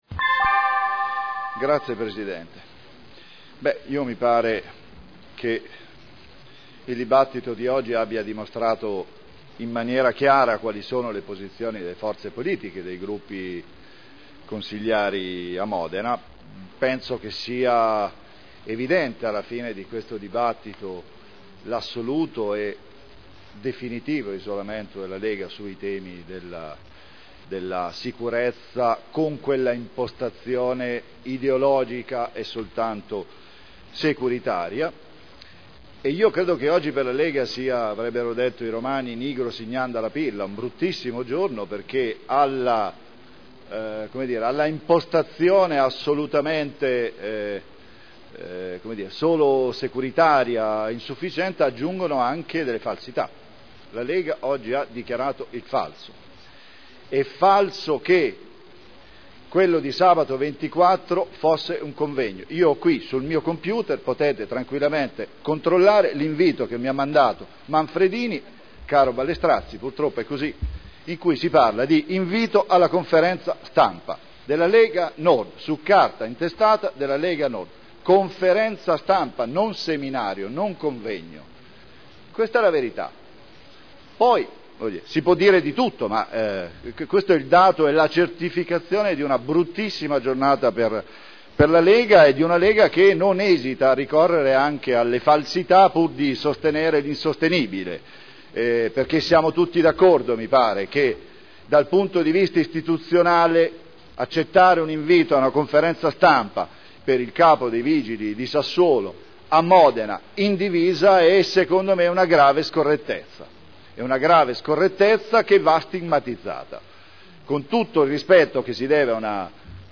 Politiche per la sicurezza della città e dei cittadini: Linee di indirizzo Dichiarazioni di voto
Seduta del 03/05/2010